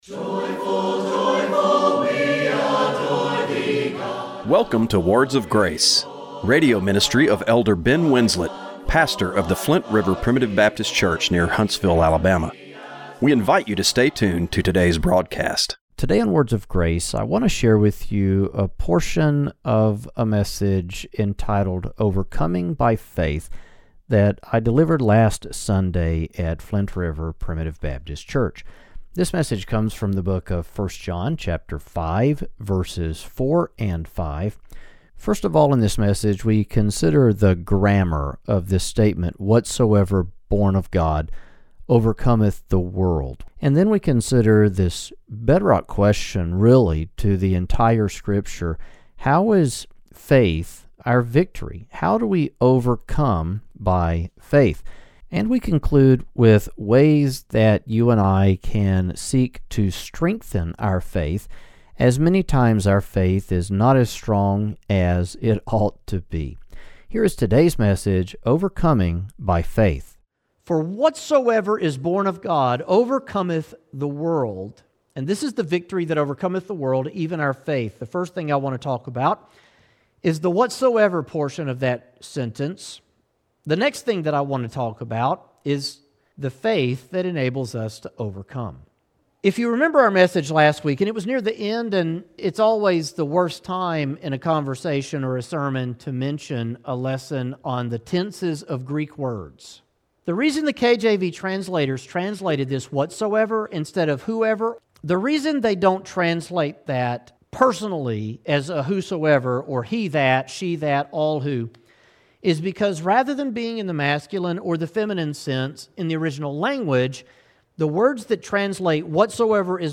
Radio broadcast for January 12, 2025.